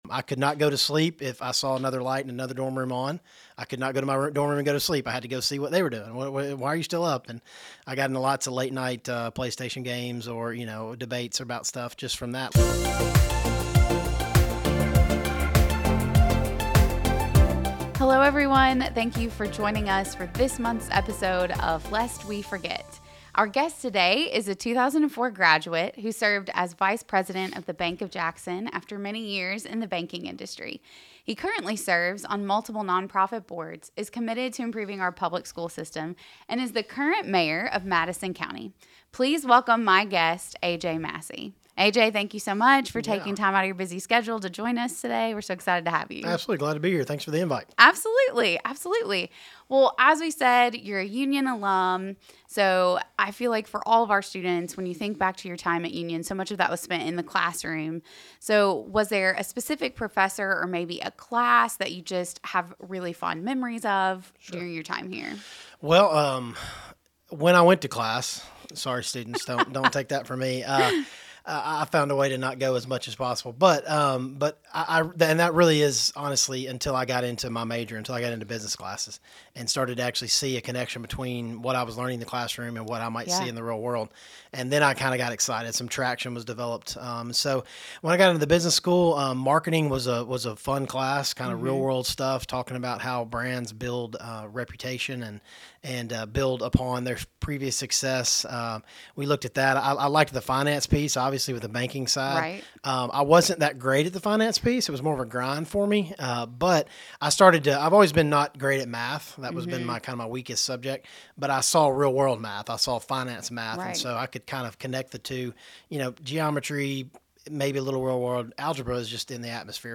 On this episode of Lest We Forget, Madison County Mayor AJ Massey stops by to chat about his time at Union, the current political climate, and how to better partner with your local leaders.